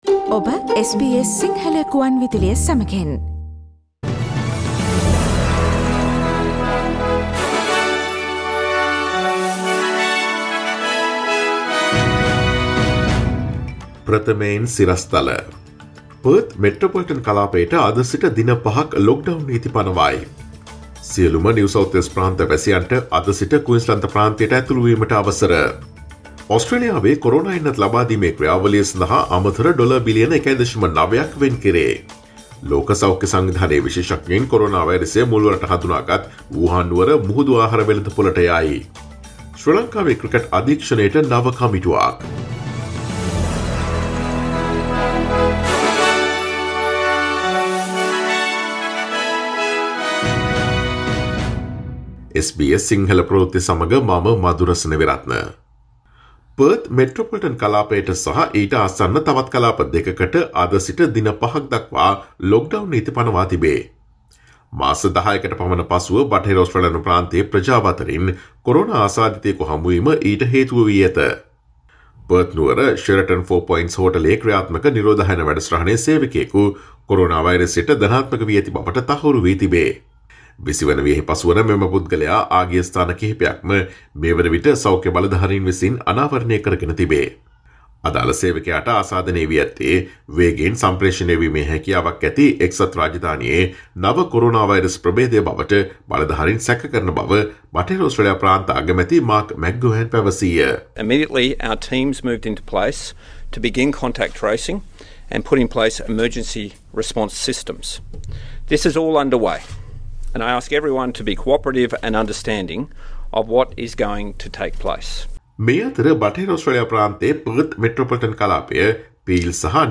Today’s news bulletin of SBS Sinhala radio – Monday 01 February